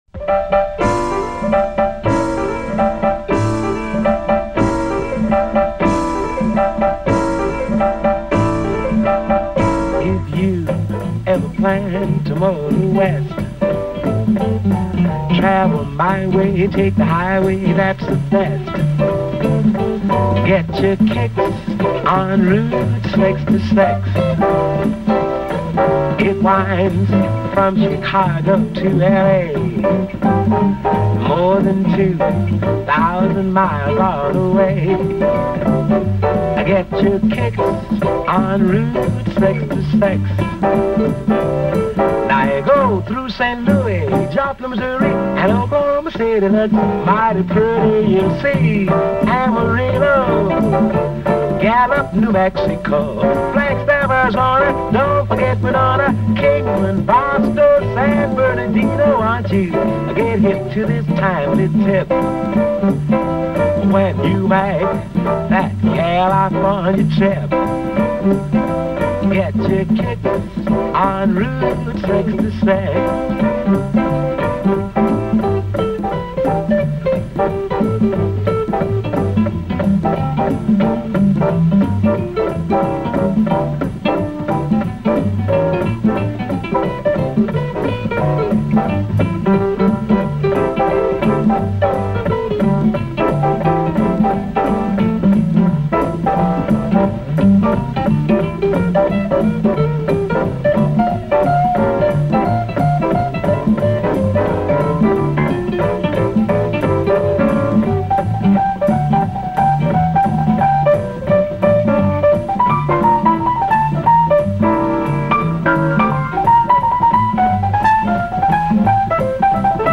JAZZ PIANO SOLOS - AUDIO FOR SOLO TRANSCRIPTIONS